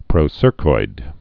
(prō-sûrkoid)